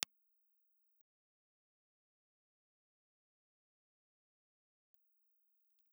Impulse Response File:
Impulse Response file of the Philips EL3750 dynamic microphone.
Philips_EL3750_IR.wav